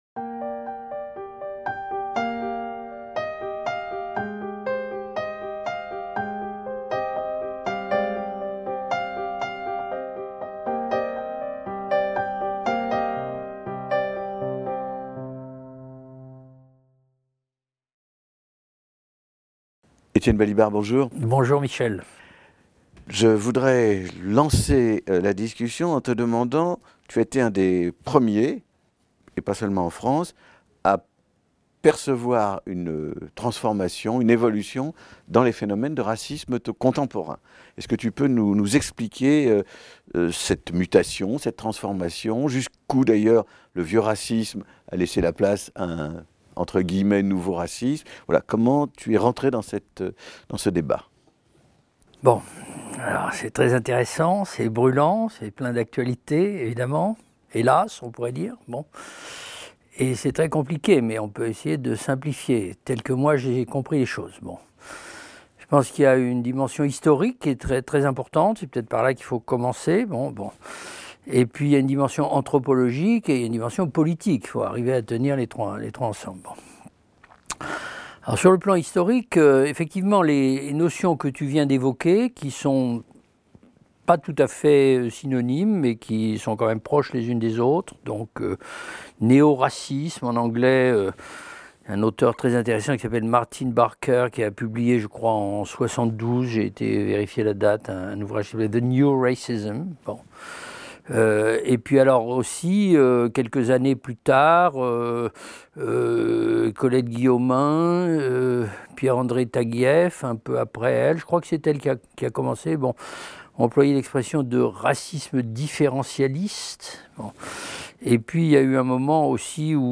Le racisme différencialiste - Un entretien avec Étienne Balibar | Canal U